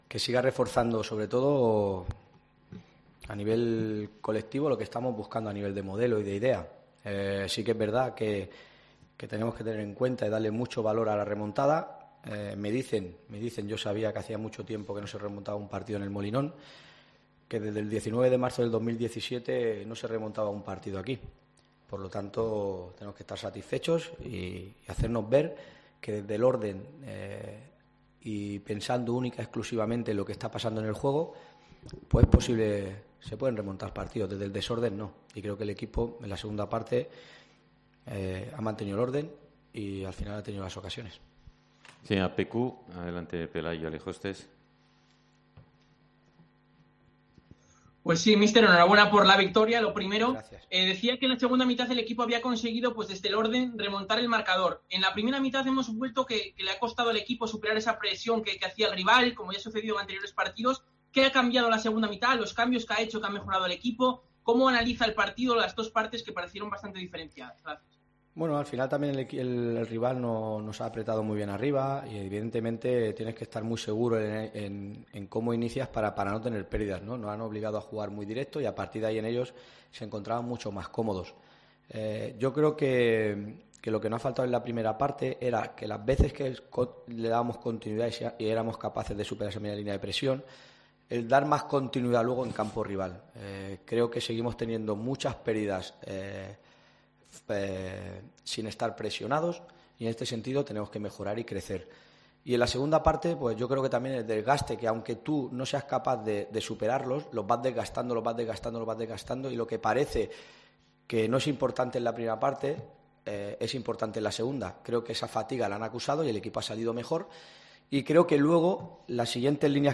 AUDIO: Escucha aquí las palabras del entrenador de la Sporting de Gijón tras la victoria 2-1 en El Molinón ante la Deportiva Ponferradina